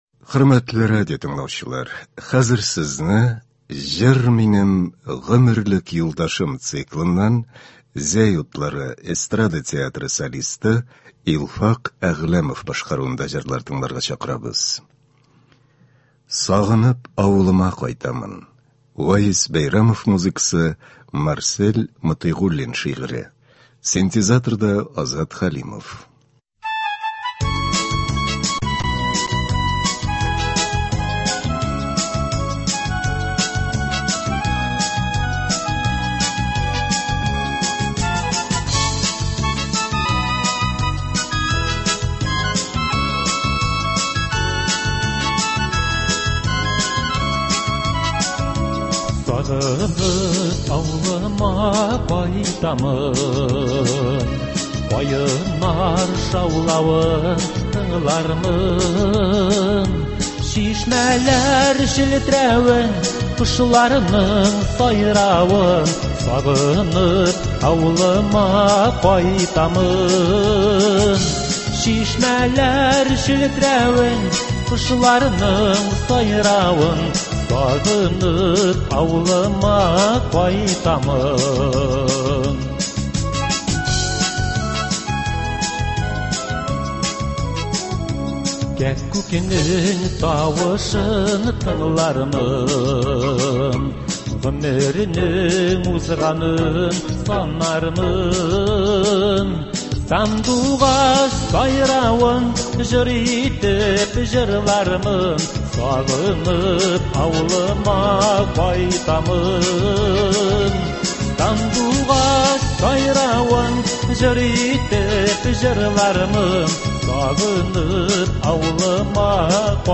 Концерт (09.10.23)